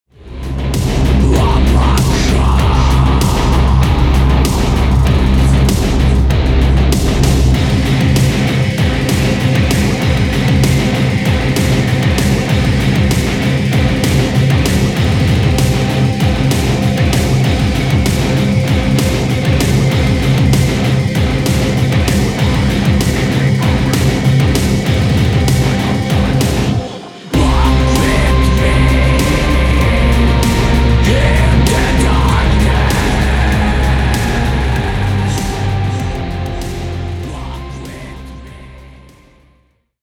Satanischer Death Industrial aus Mexico.